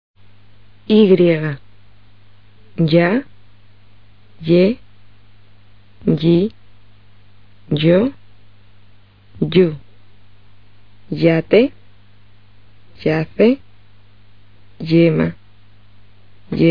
y在元音之前是舌前硬腭擦浊辅音[j]，在一些地区发音与ll相同。在元音之后或单独使用时，发[i]